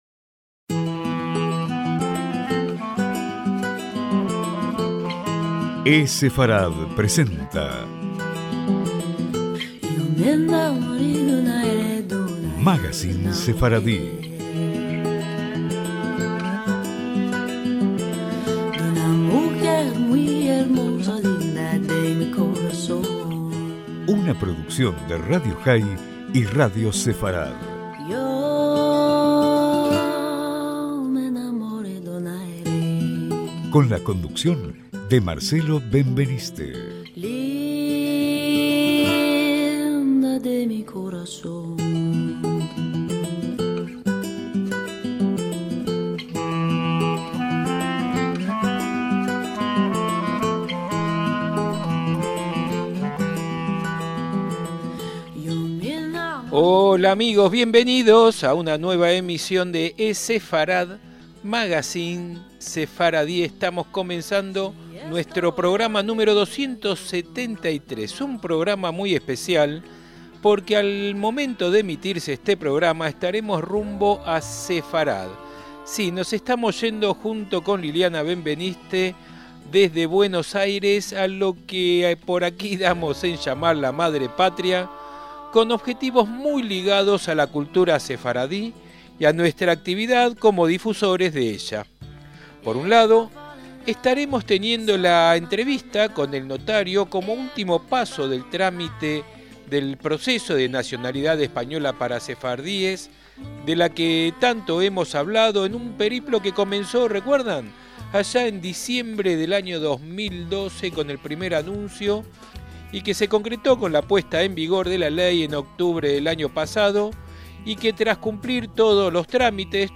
ESEFARAD: MAGACÍN SEFARDI – Un programa musical con distintas fuentes de música sefardí